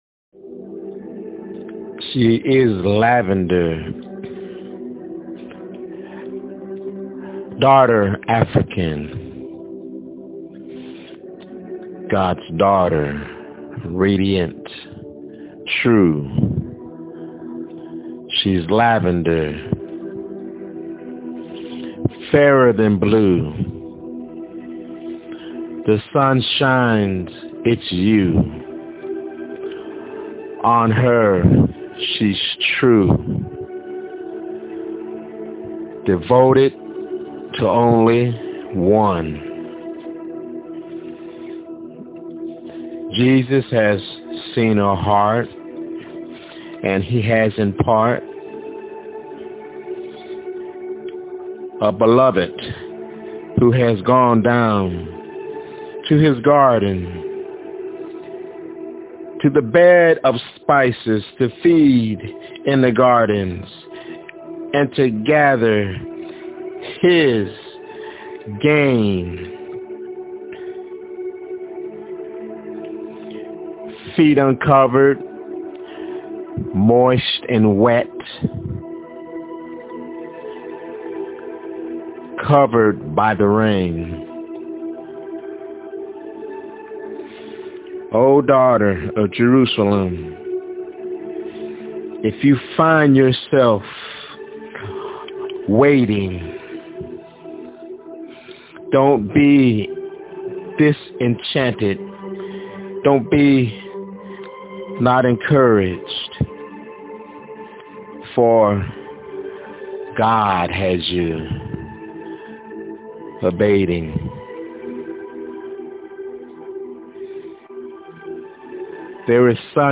She's Lavender- My Spokenword